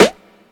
Snares
emn snare 1.wav